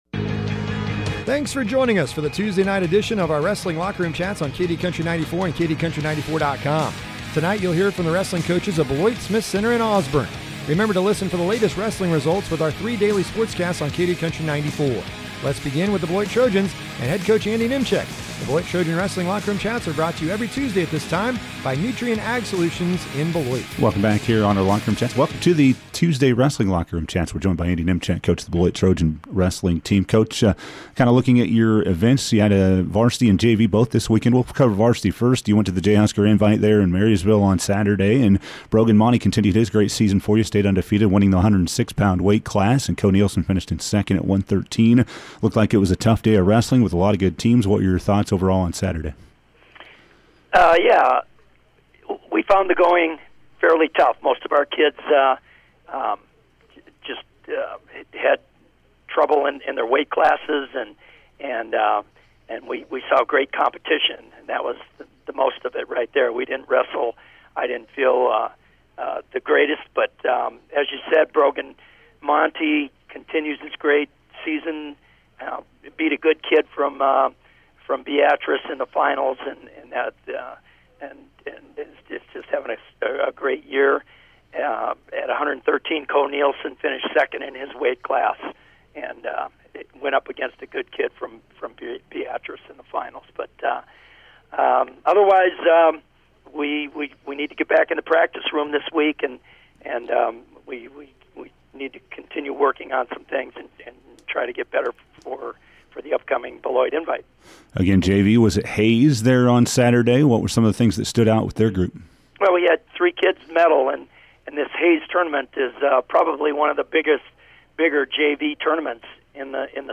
talk with the head wrestling coaches